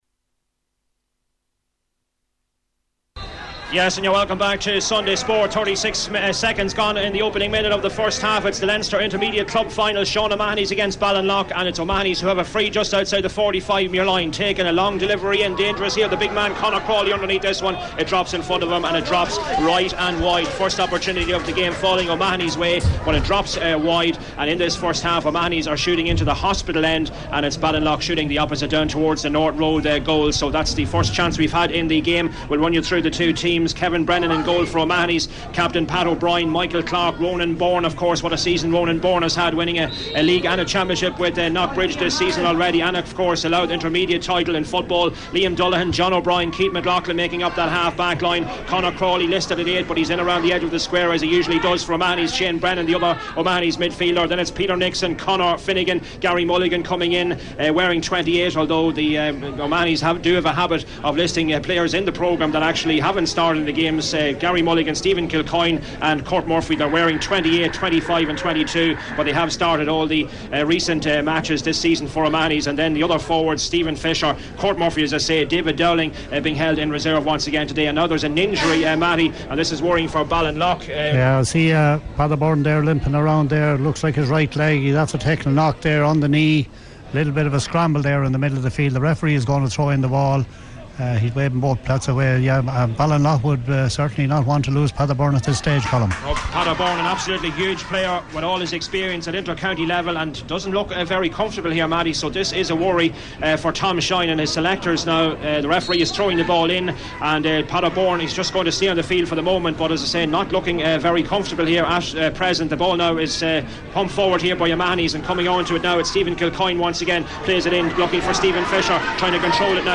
Sean O'Mahonys v Ballinlough, LMFM match commentary